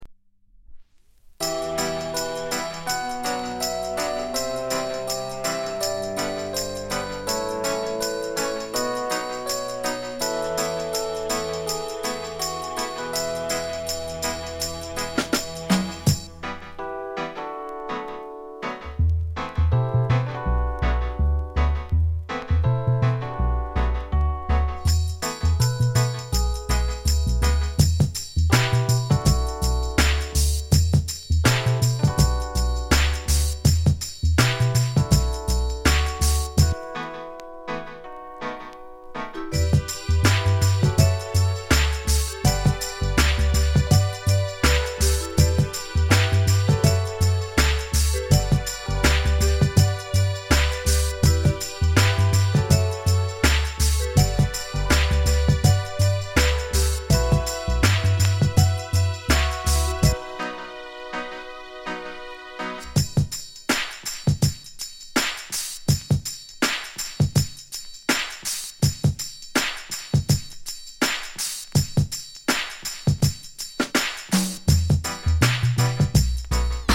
日本? 7inch/45s